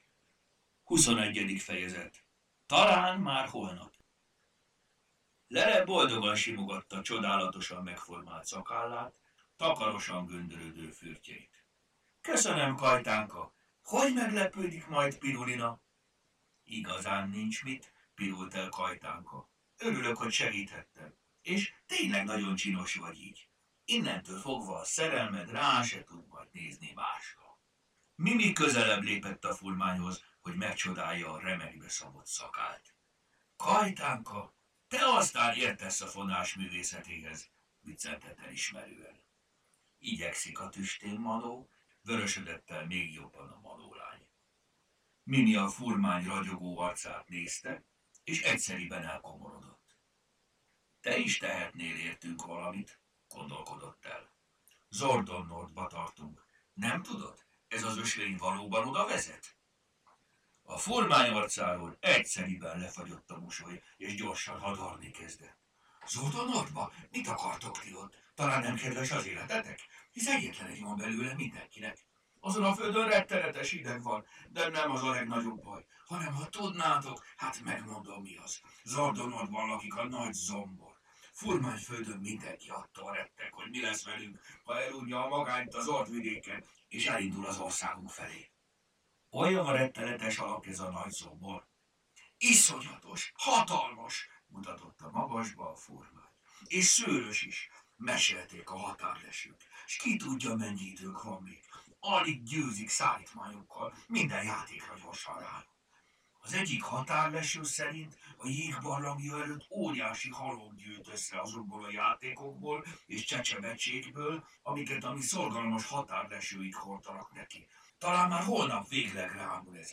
Hangos mese: Talán már holnap…